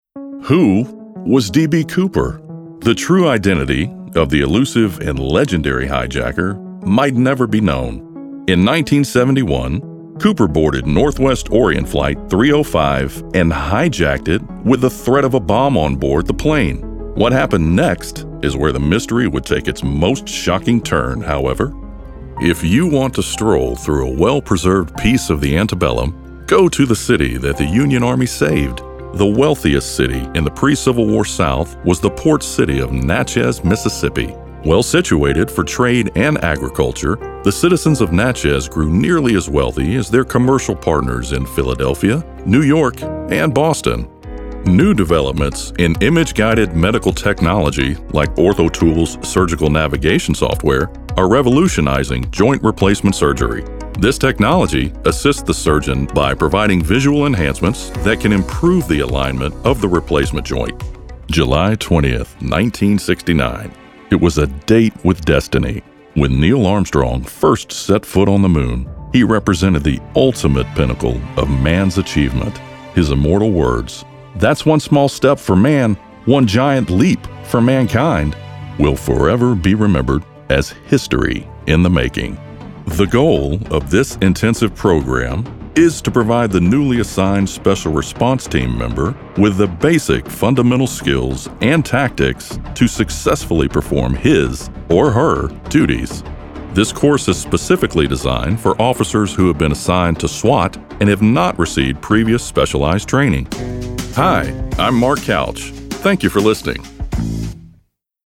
A smooth and persuasive voice to help promote your product and engage your audience.
Southern, Western
Middle Aged